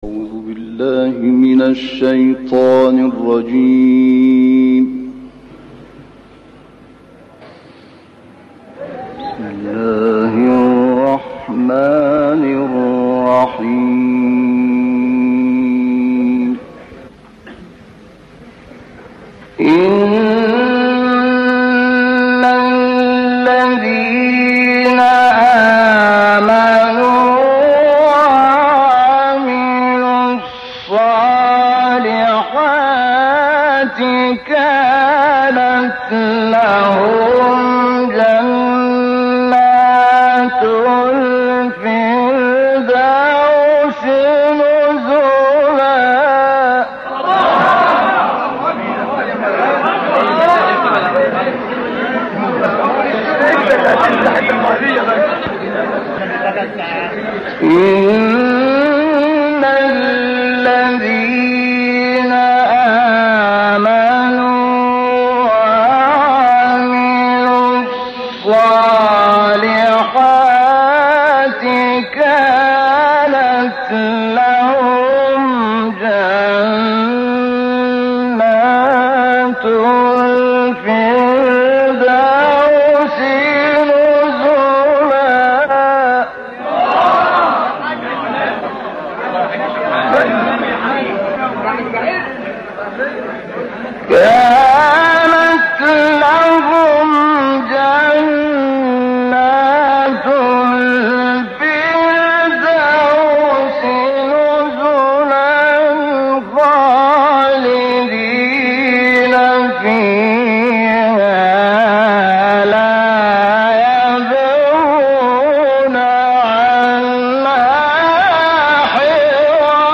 گروه فعالیت‌های قرآنی ــ بعضی از اساتید، ویژگی‌های موسیقیایی قرائت شحات محمد انور را به ردیف و موسیقی ایرانی خیلی نزدیک دانسته‌اند و این موضوع را عامل جذبه تلاوت ایشان برای ایرانی‌ها می‌دانند.
تحریرهای بسیار هنرمندانه، دقیق و ردیف‌های بسیار منظم و ترتیب‌های مجزا و فاصله‌دار که همه جزئیات را در آن به‌کار می‌برد، باعث شده است که شحات انور، مورد وثوق قرار گیرد و خیلی‌ها مقلد وی شوند.